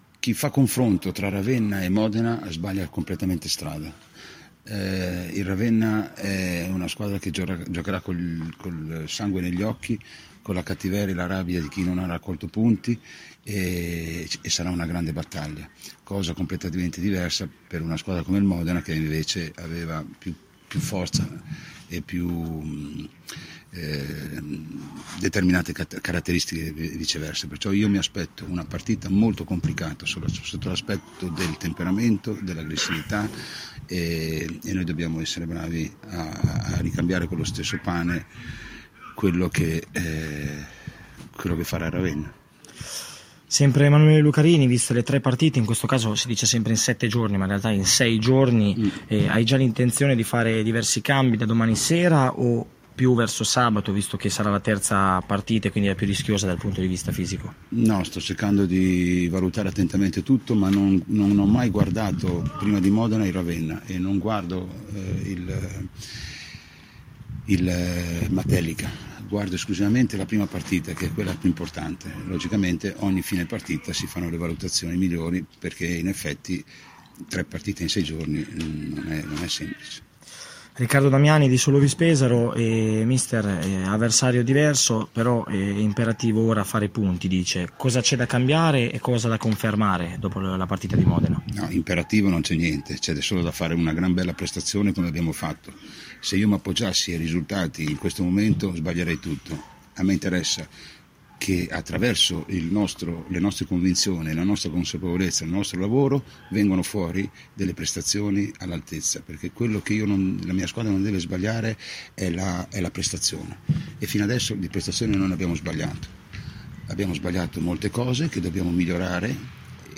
intervista-galderisi-pre-ravenna.mp3